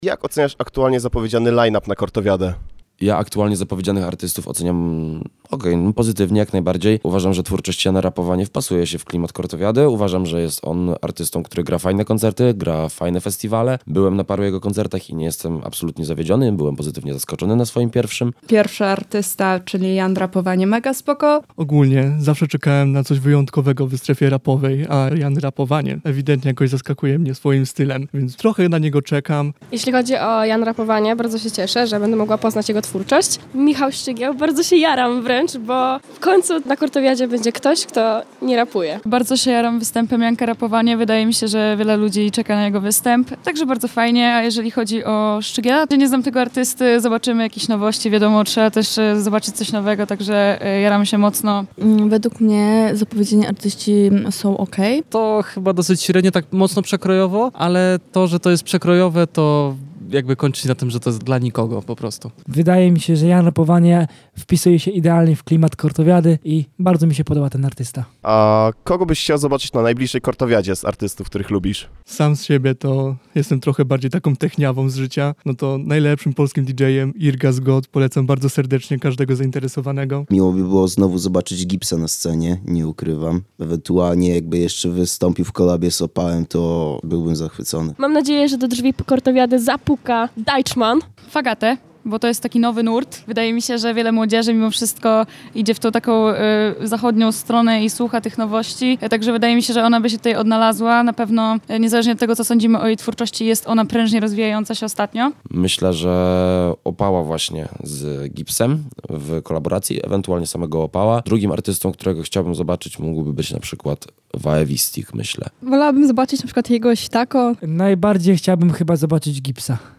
2310-sonda-kortowiada.mp3